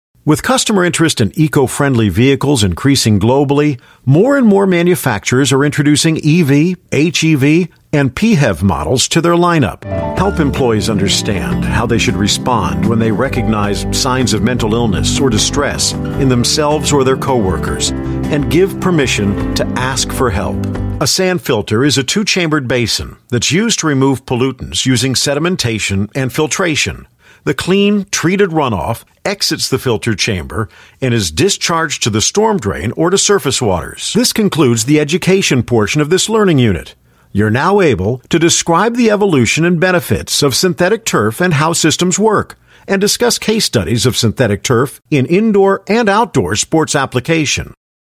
US ENGLISH
eLearning